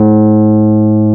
Index of /90_sSampleCDs/Roland - Rhythm Section/KEY_E.Pianos/KEY_Rhodes
KEY RHODS 00.wav